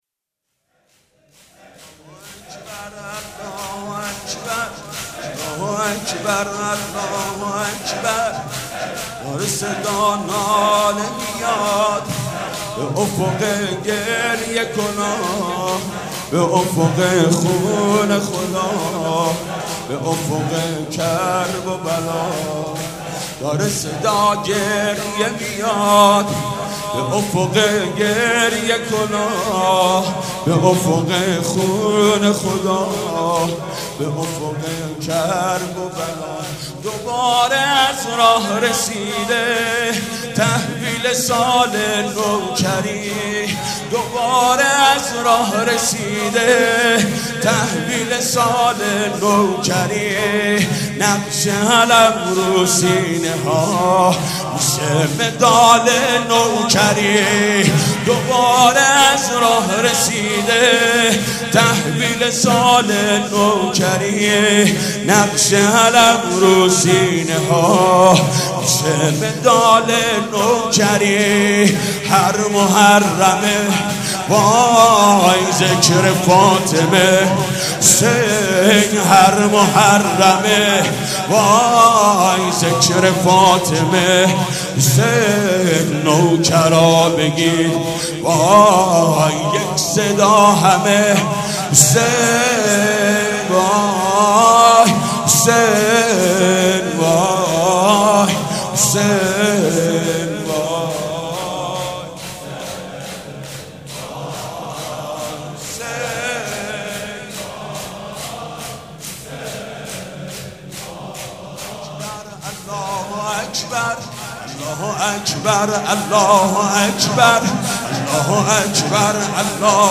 شور
مداح
مراسم عزاداری شب ششم